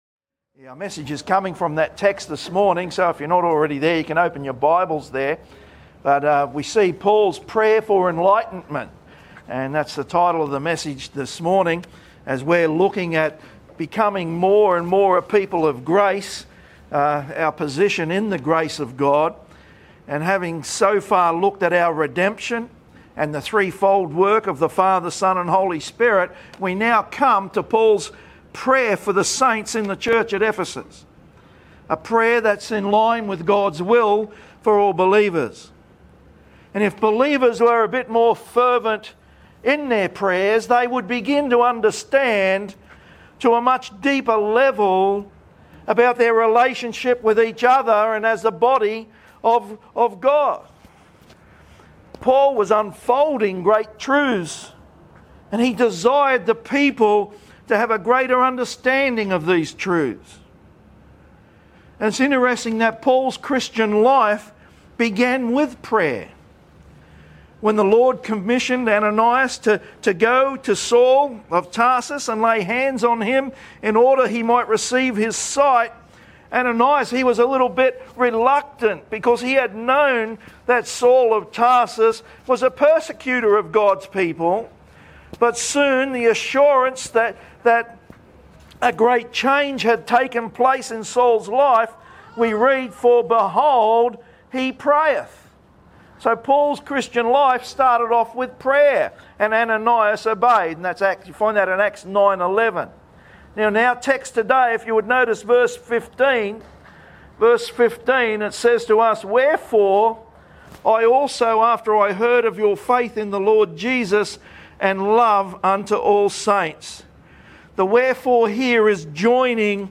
Bible Baptist Church of South East Qld Paul's Prayer for Enlightenment May 18 2025 | 00:32:54 1x 00:00 / 00:32:54 Subscribe Share Spotify RSS Feed Share Link Embed